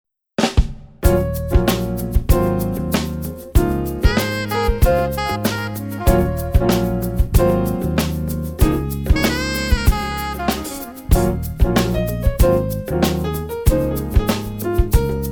Latin Jazz
8 bar intro
samba